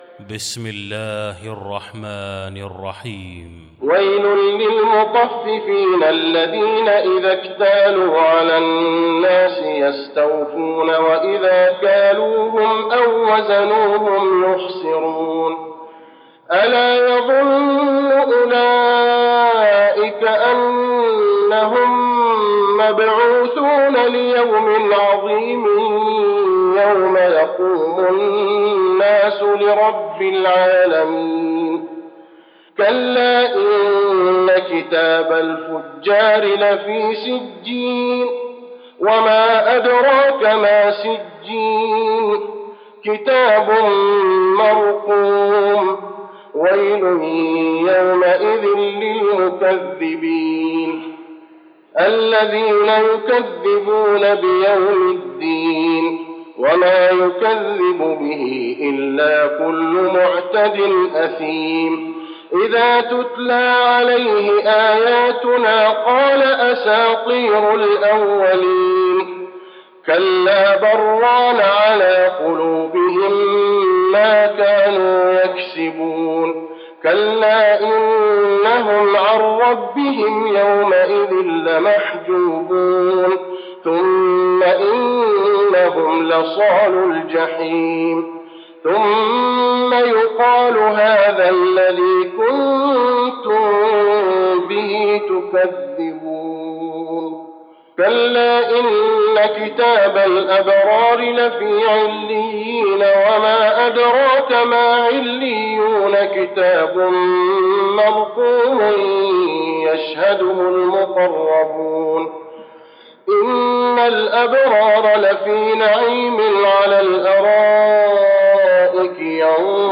المكان: المسجد النبوي المطففين